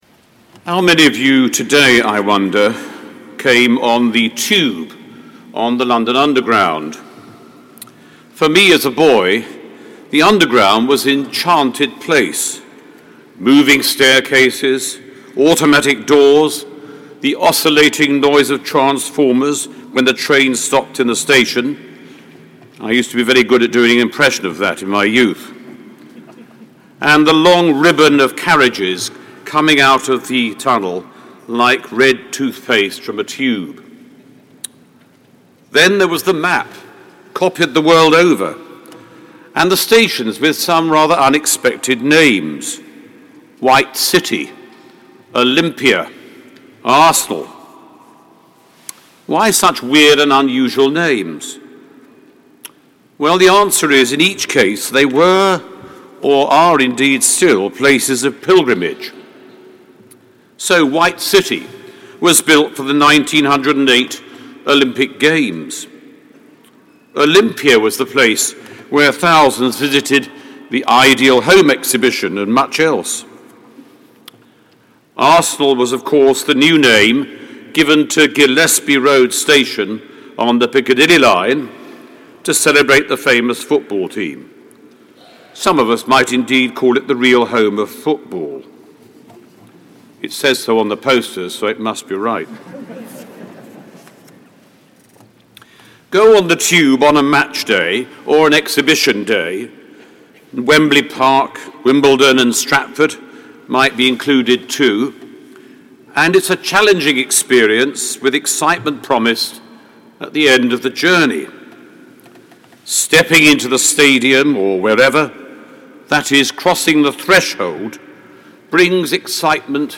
Sermon given by The Right Reverend Stephen Platten, Honorary Assistant Bishop, Diocese of London, at the Festival Eucharist on the National Pilgrimage to the Shrine of St Edward the Confessor - 17th October 2015